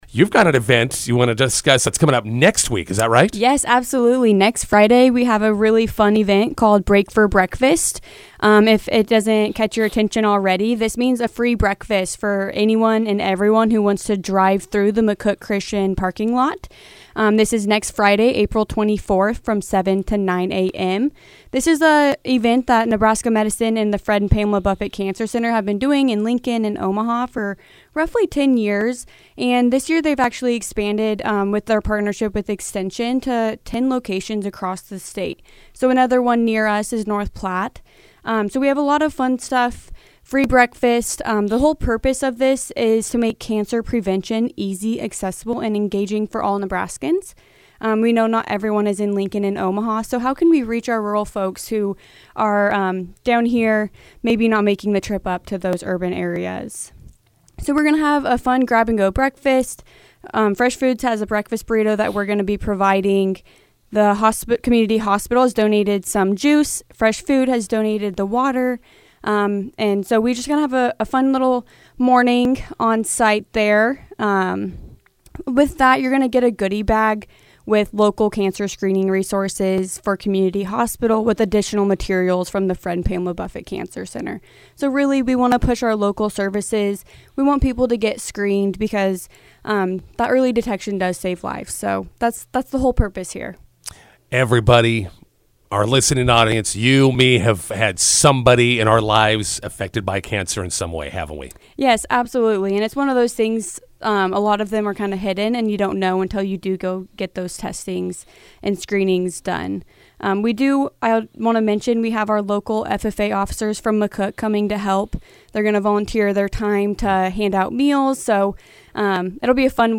INTERVIEW: Brake for Breakfast event at McCook Christian Church on Friday.